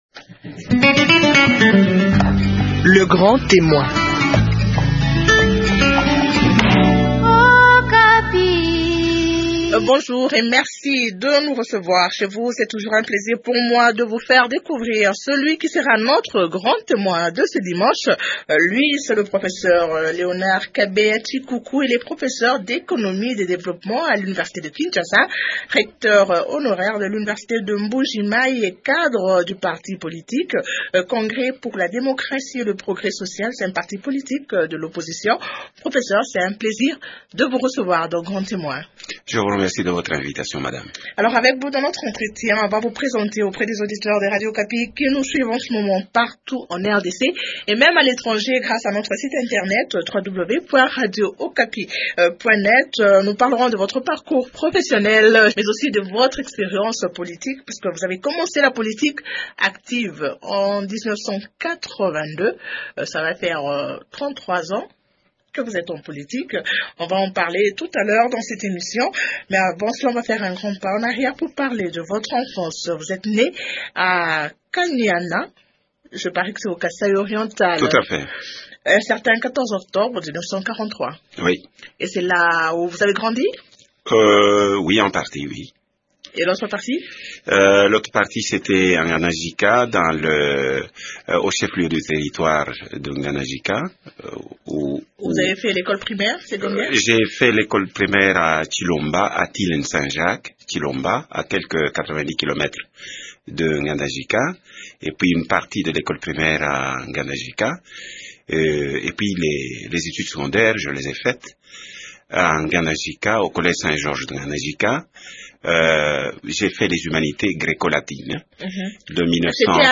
Il évoque aussi dans cet entretien les raisons qui ont conduit à la création du CDPS. Il critique également le fonctionnement des partis politiques en RDC.